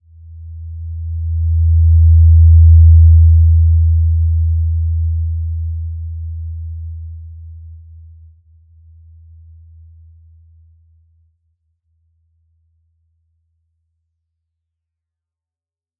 Simple-Glow-E2-mf.wav